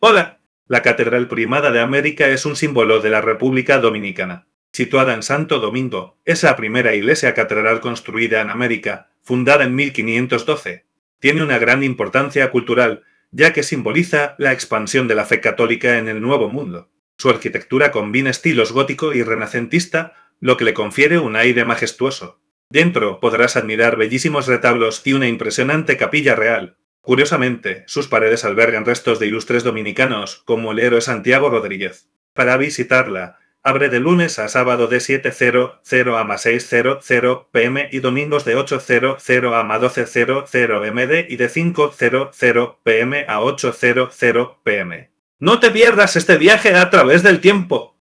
karibeo_api / tts / cache / 3604f8937eadf07e28645fddf451eeaa.wav